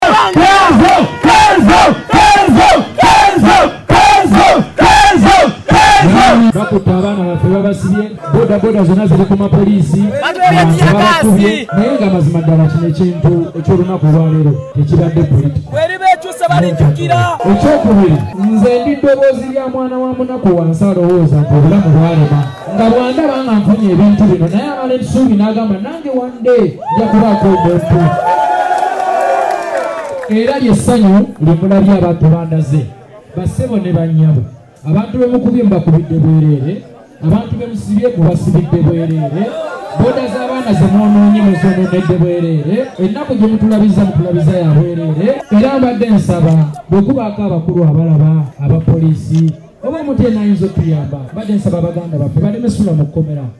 AUDIO! Ndi mwetegefu okusibwa singa mugaana, Kenzo awadde Poliisi nsalesale nga musunguwavu
Wabula Kenzo bwe yabadde ayogerako eri bannamawulire mu bitundu bye Makindye yasabye Poliisi okuyimbula abavubuka bonna abaakwattiddwa kuba tebalina musango wabula okwagala omuntu waabwe (Kenzo) y’emu ku nsonga lwaki bazze Entebbe.
Eddoboozi lya Kenzo